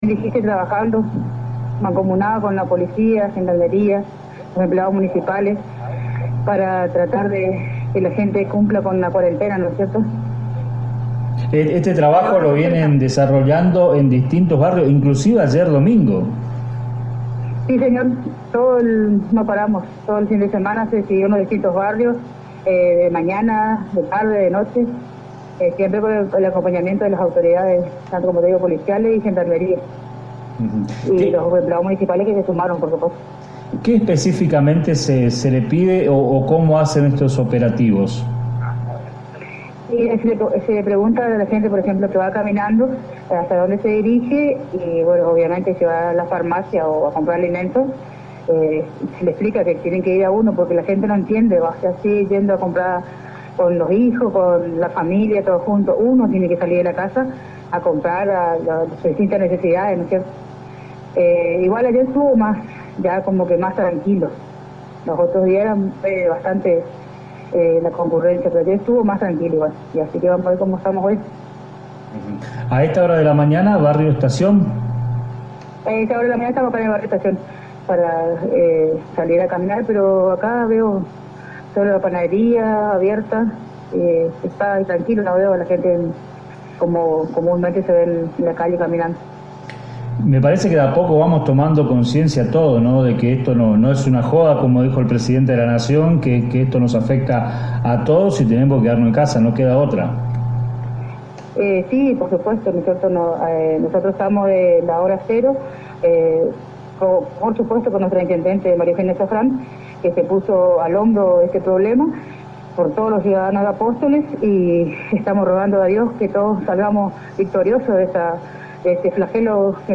En diálogo con Radio Estilo y ANG